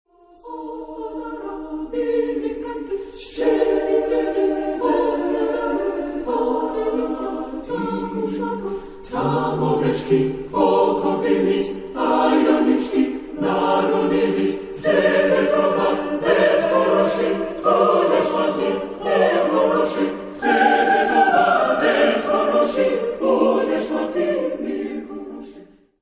Genre-Style-Form: Secular ; Sacred ; Folk music ; Carol
Mood of the piece: allegretto
Type of Choir: SATB  (4 mixed voices )
Tonality: G minor
Keywords: happiness ; ewe ; swallow ; sheep ; money ; bell ; a cappella